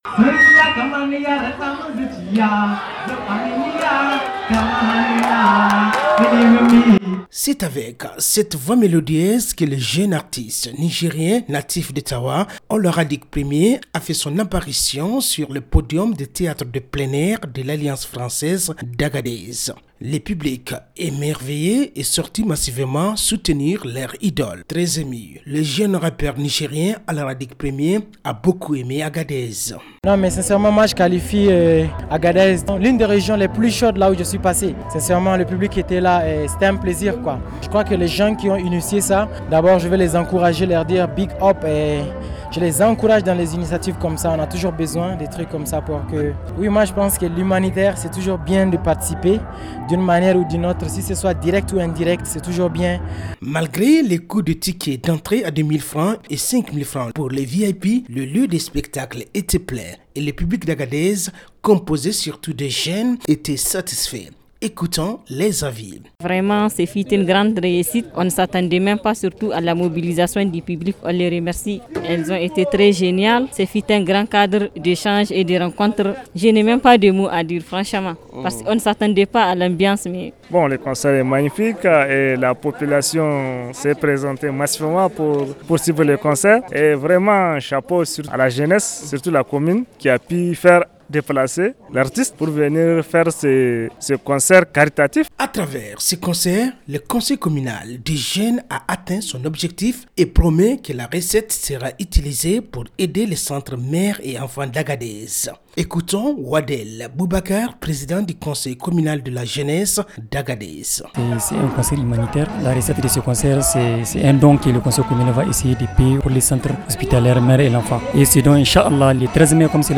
Magazine en français